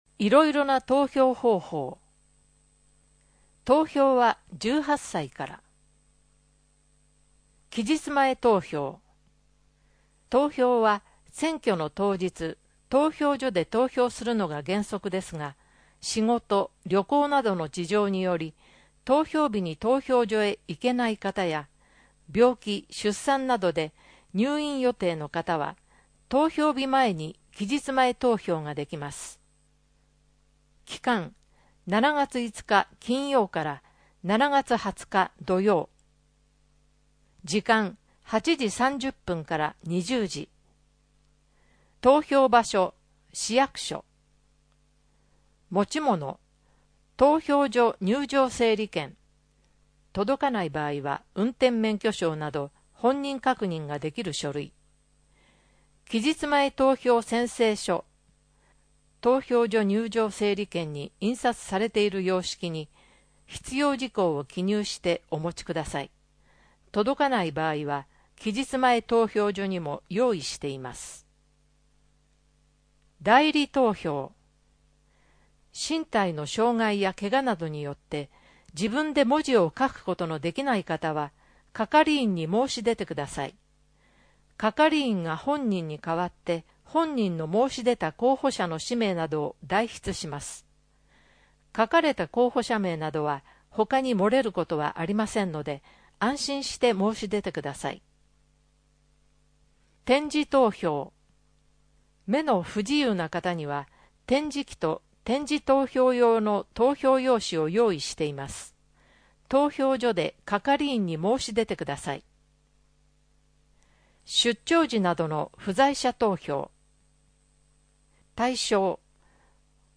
14 奥付（47秒）声の広報つるがしまは、「鶴ヶ島音訳ボランティアサークルせせらぎ」の皆さんが「広報つるがしま」の内容を音訳し、「デイジー鶴ヶ島」の皆さんがデイジー版CDを製作して、目の不自由な方々へ配布をしています。